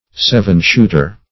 Seven-shooter \Sev"en-shoot`er\, n.
seven-shooter.mp3